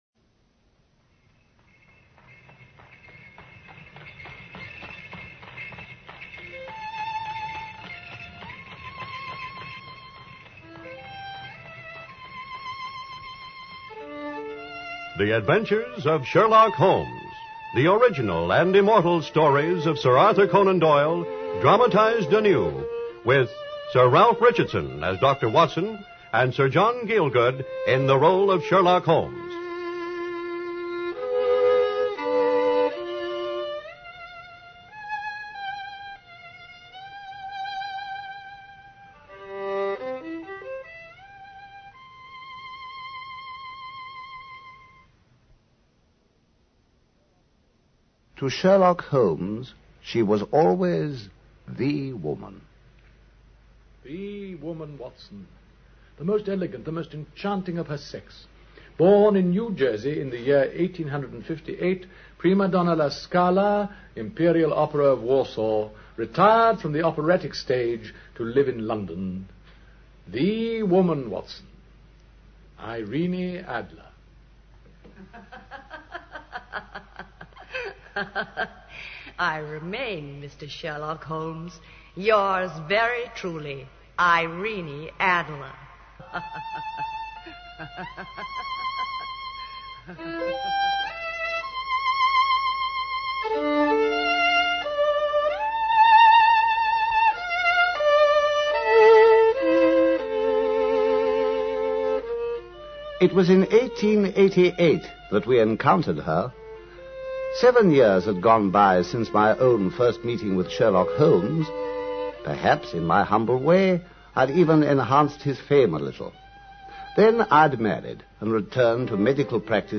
Radio Show Drama with Sherlock Holmes - A Scandal In Bohemia 1954